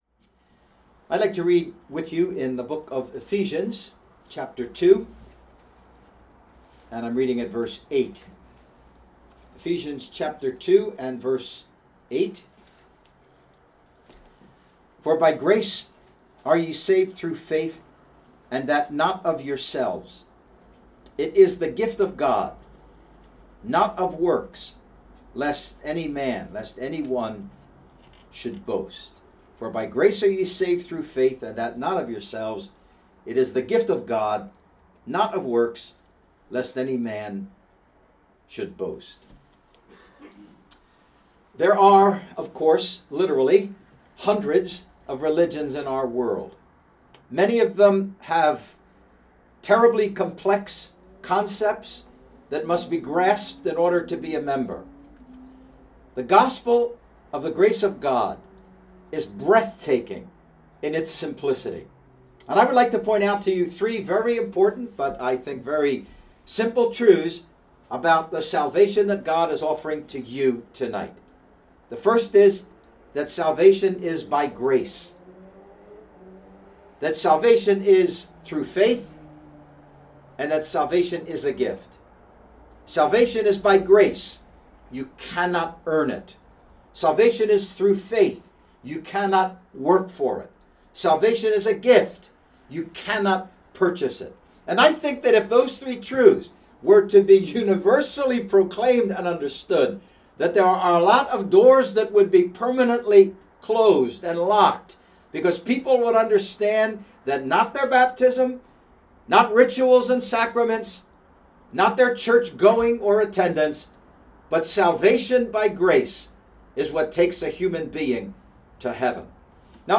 Gospel Meetings